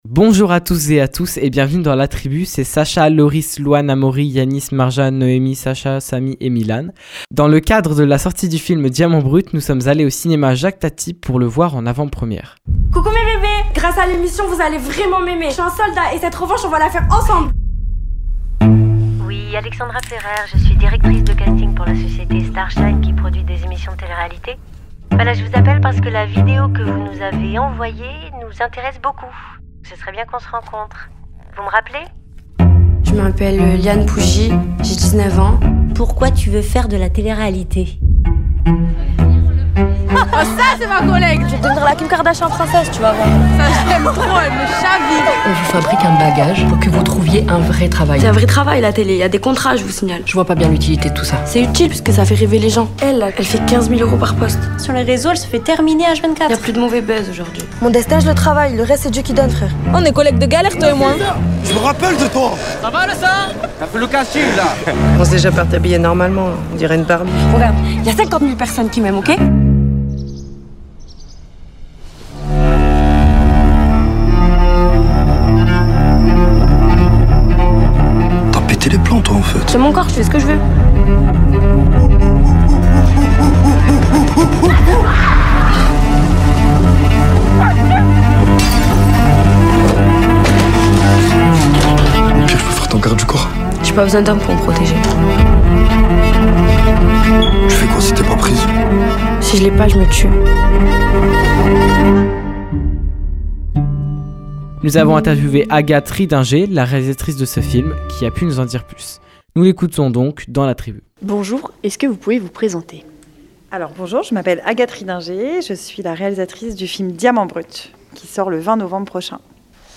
Émission des jeunes d’Escalado.